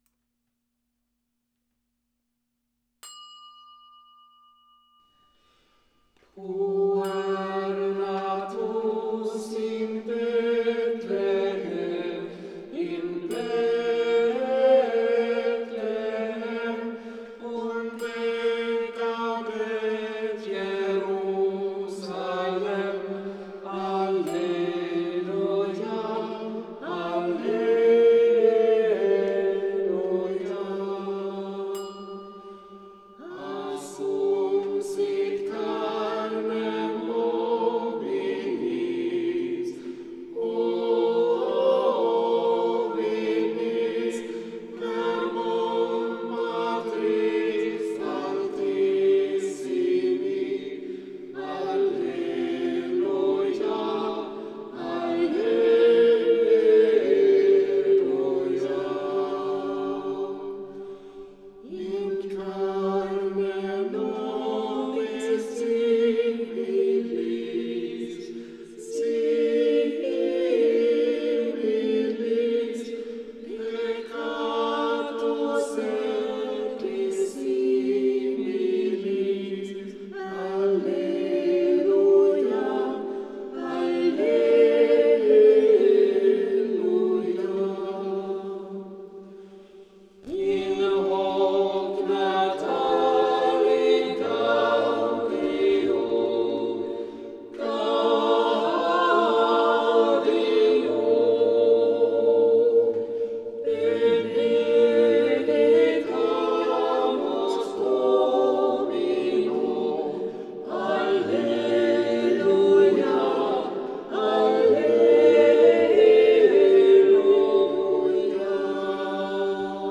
Vokalensemblen KALK
Puer natus in Bethlehem i 2-stämmig version från utgåvan år 1582 och 4-stämmig version från utgåvan år 1625.